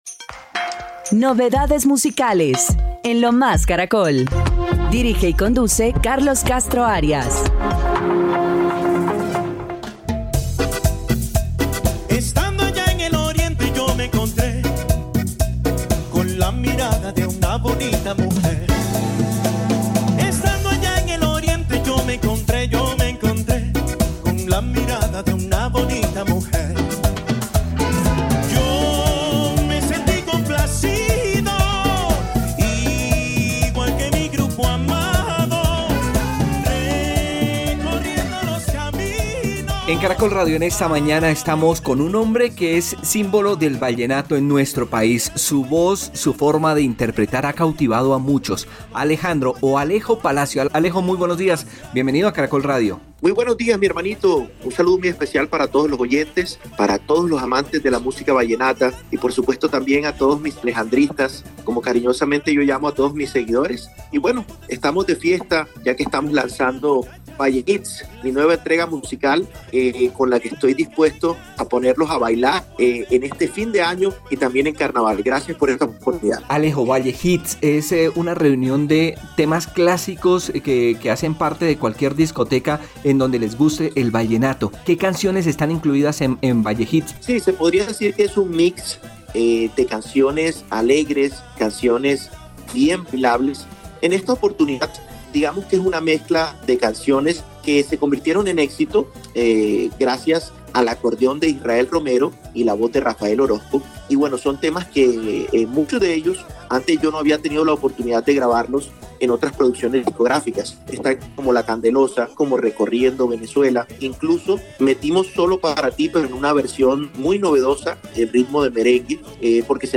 una versión merenguera